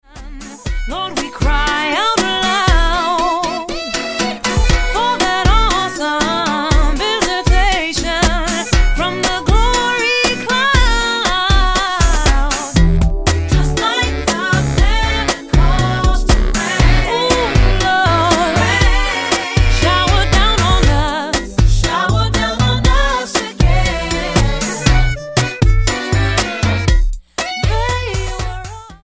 STYLE: Gospel
A delicious blend of R&B soul and latin rhythms